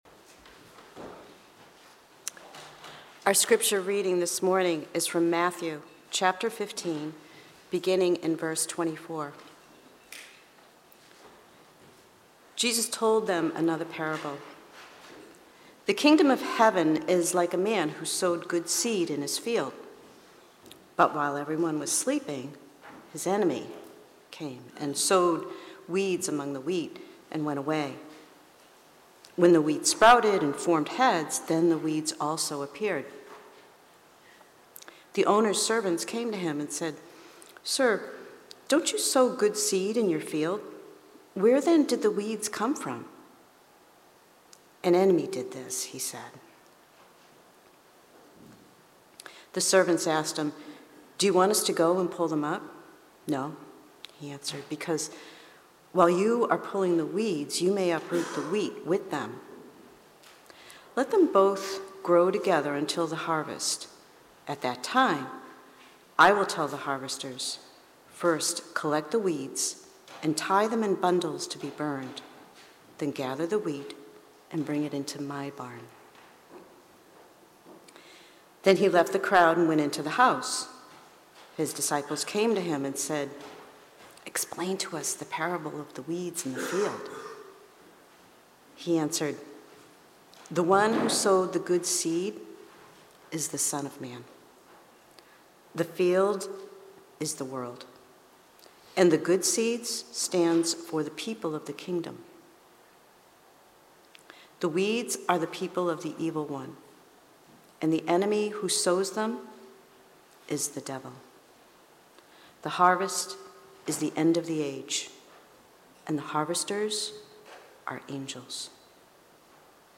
Sermons – North Shore Community Baptist Church